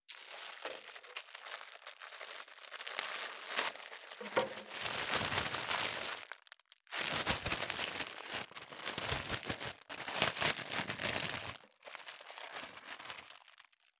636コンビニ袋,ゴミ袋,スーパーの袋,袋,買い出しの音,ゴミ出しの音,袋を運ぶ音,
効果音